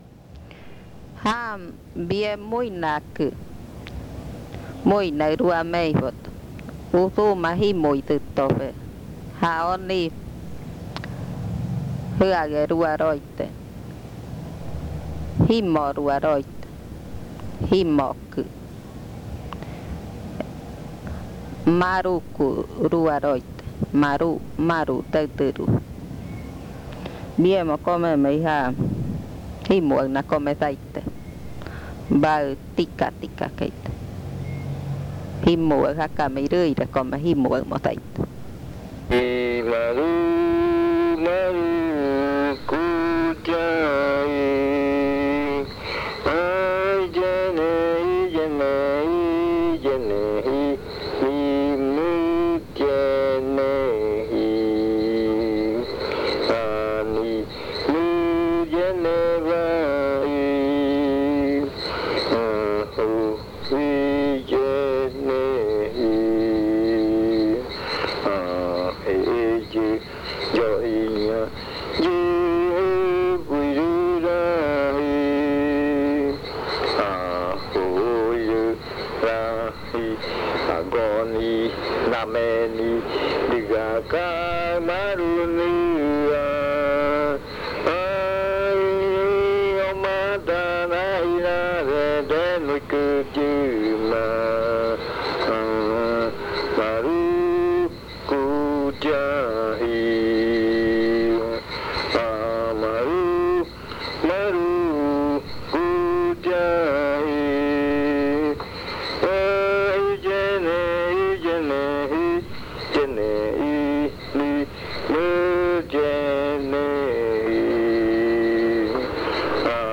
Leticia, Amazonas
Canción Jimoma rua, canto de maruku ave nocturna, familia de lechuza. (Casete original
Cantos de yuakɨ